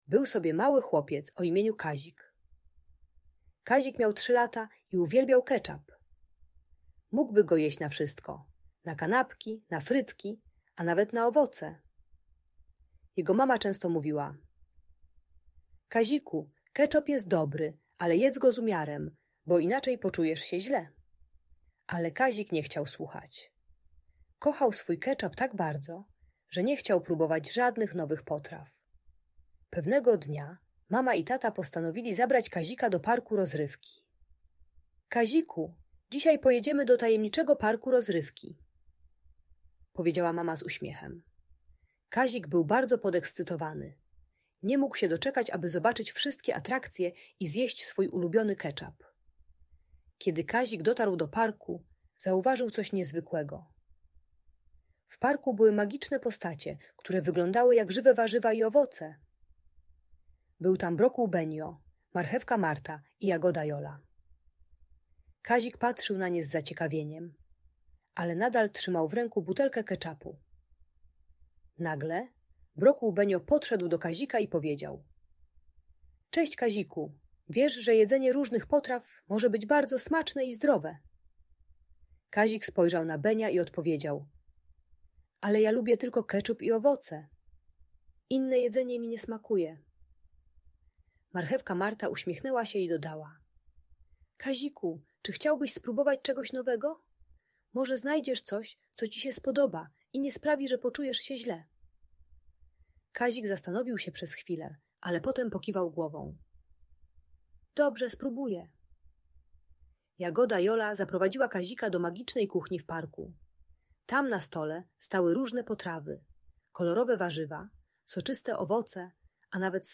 Przygoda z Nowymi Smakami - Audiobajka